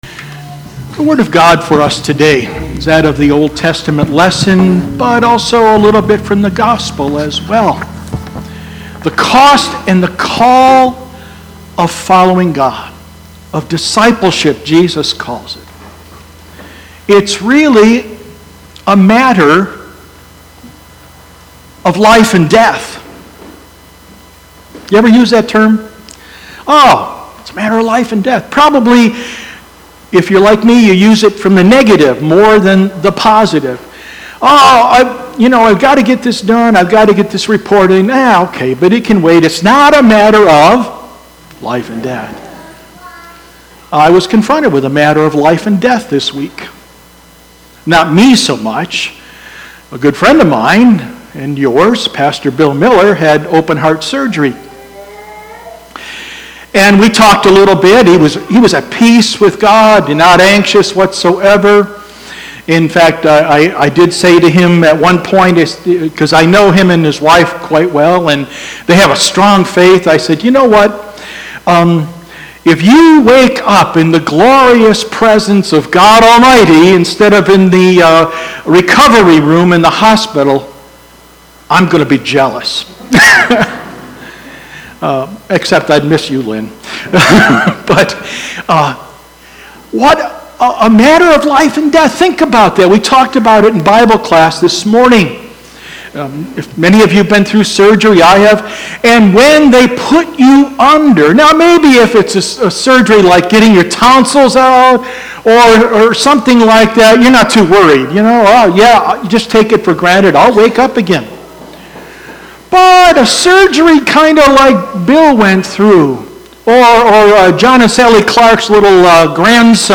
Sermon-9_8.mp3